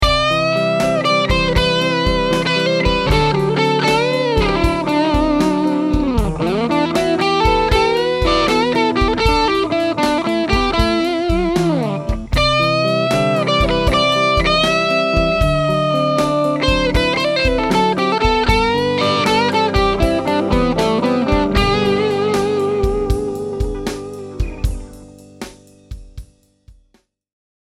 The Burstbucker 3 produces a rich tone that’s full of harmonics and overtones, without being overly bright.
Dirty Lead
I used a Sennheiser e609 instrument mic, and recorded directly into GarageBand with no volume leveling.
treb_dirty.mp3